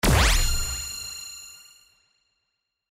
powerball_drop.mp3